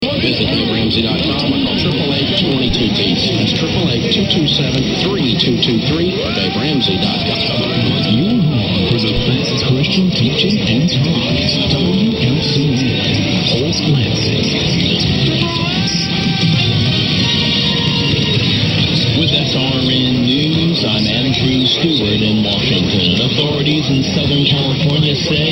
I had some booming signals here this morning from some of the common d=stations.
Anyway here are some recordings from today here on the West of Scotland.